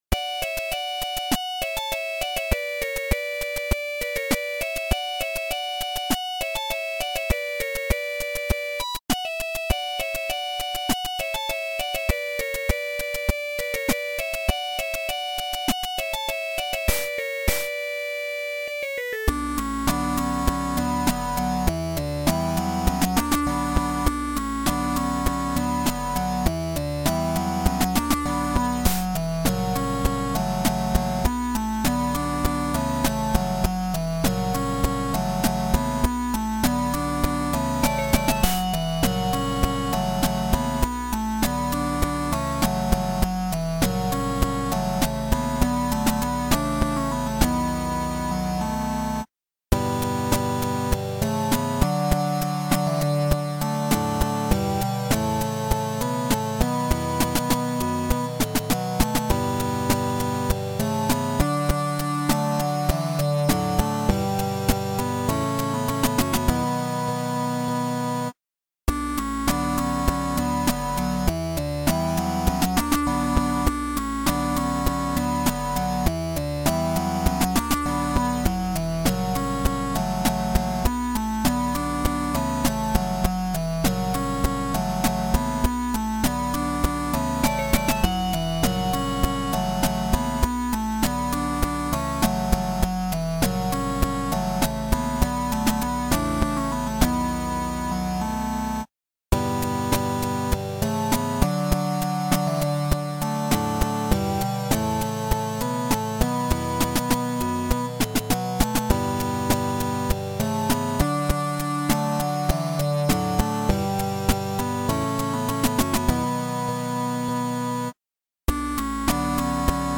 Submission file is just the intro music.